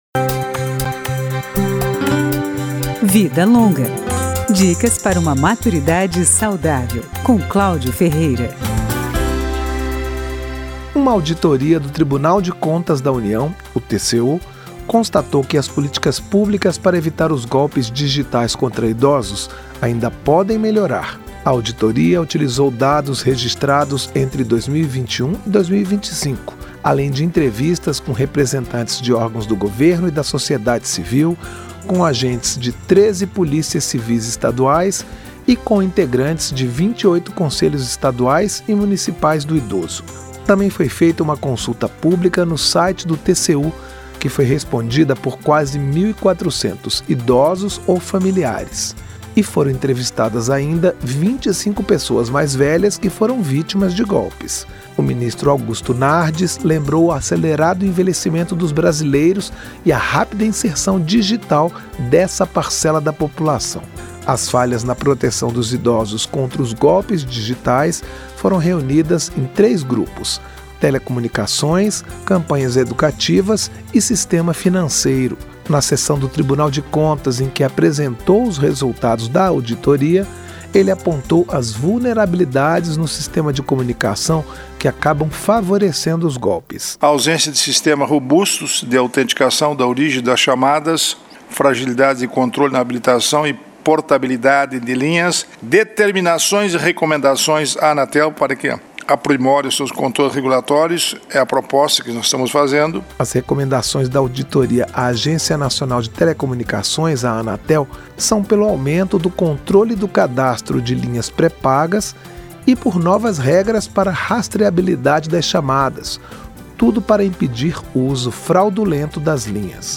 Profissionais de várias áreas falam sobre alimentação, cuidados com a saúde, atividades físicas, consumo de drogas (álcool, cigarro) e outros temas, sempre direcionando seus conselhos para quem tem mais de 60 anos.